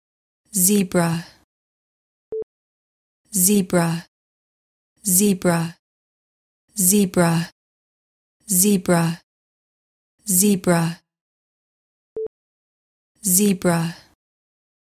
描述：女人说"斑马"（zeebra不是zehbra）。修理后的之前。两端的原件。